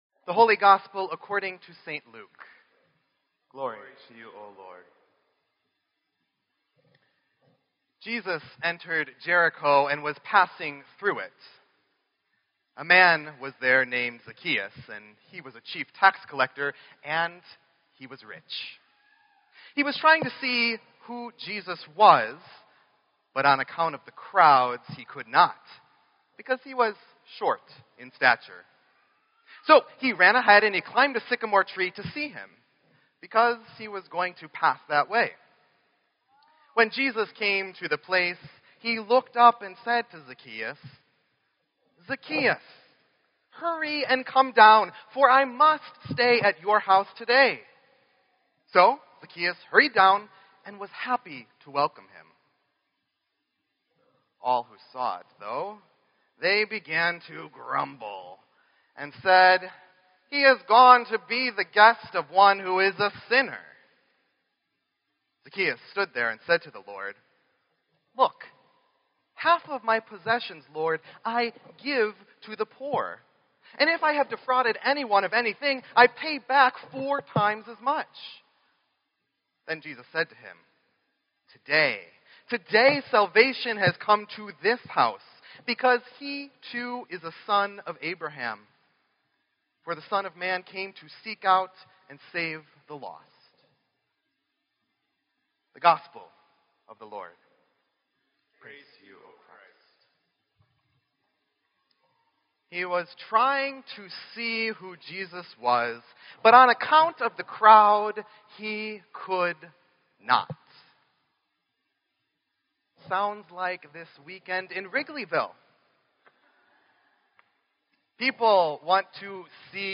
Reformation/Homecoming Sunday Wicker Park Lutheran church